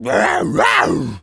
tas_devil_cartoon_01.wav